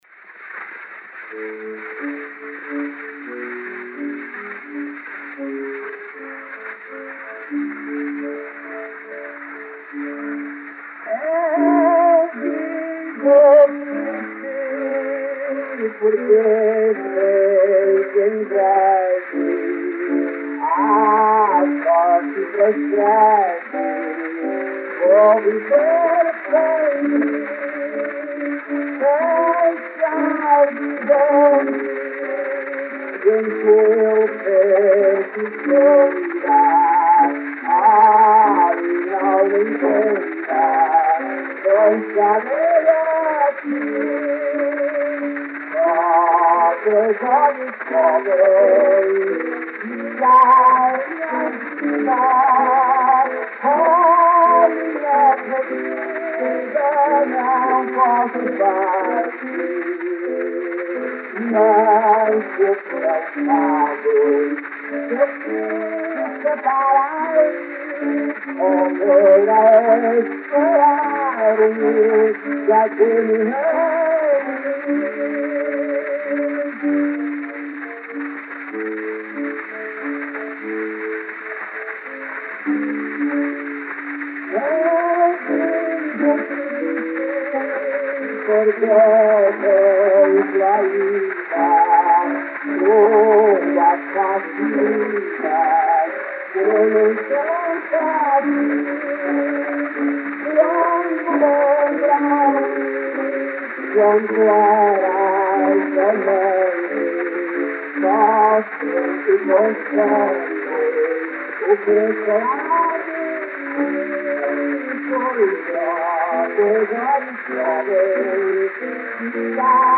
O gênero musical foi descrito como "modinha".